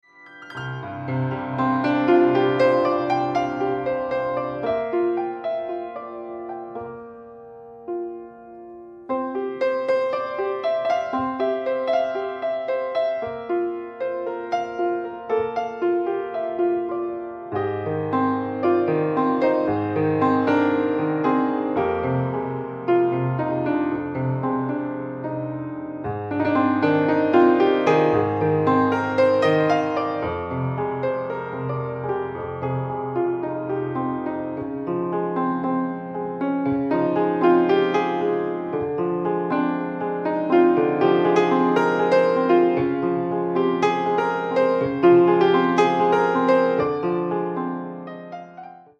Piano solo "smooth".
En su comienzo, esta pieza fue una improvisación.